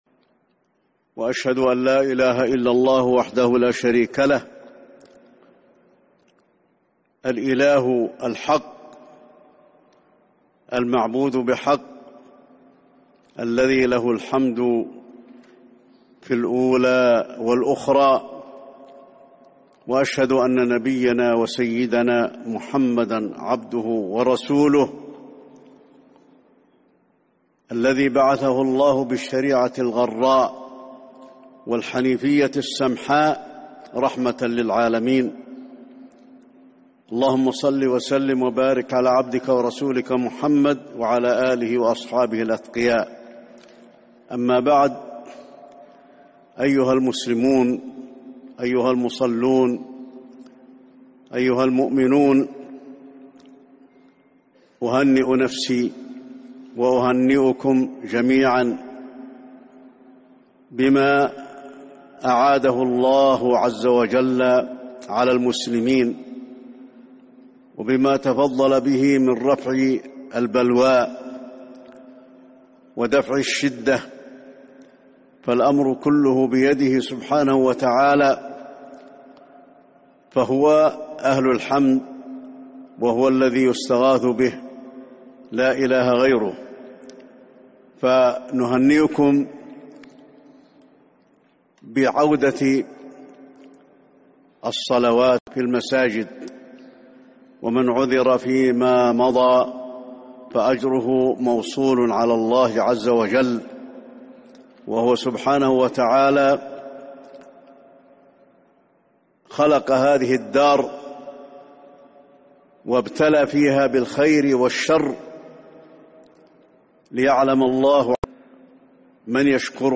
كلمة الشيخ الحذيفي بعد صلاة الفجر بمناسبة عودة المصلين بالمسجد النبوي 8 شوال 1441 بعد انتشار وباء كورونا